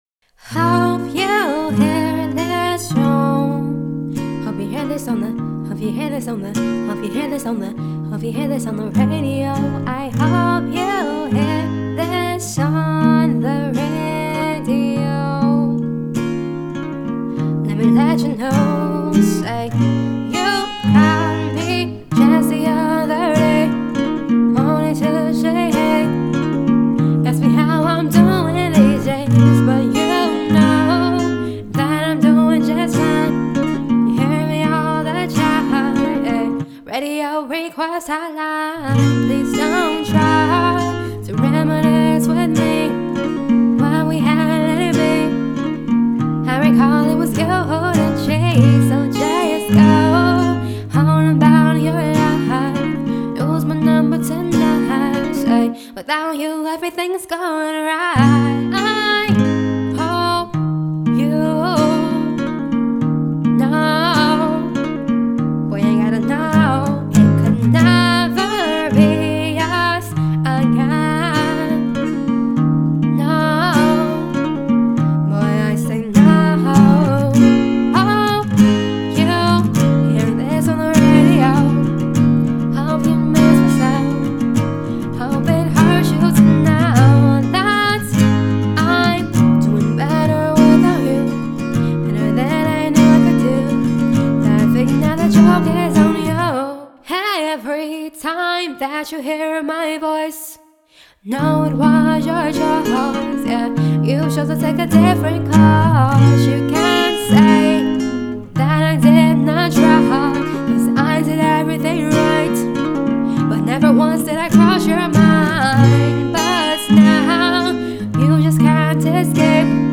Genres: Acoustic, R&B